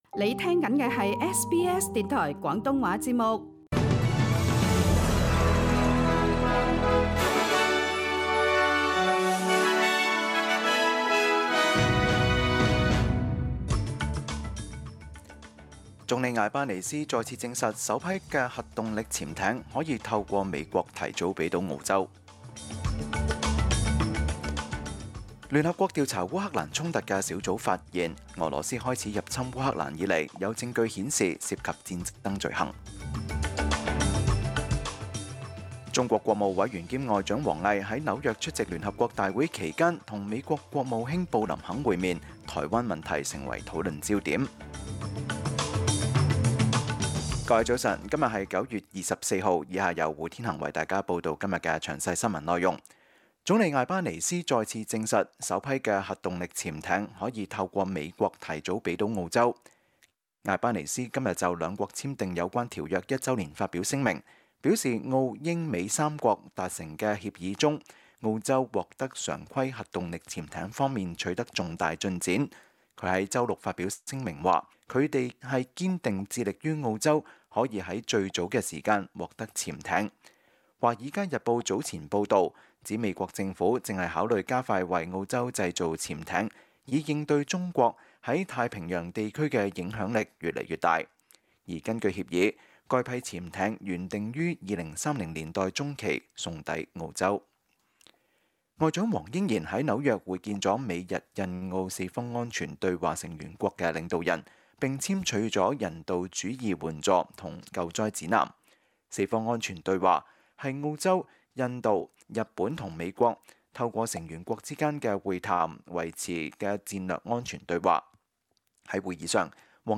SBS 廣東話節目中文新聞 Source: SBS / SBS News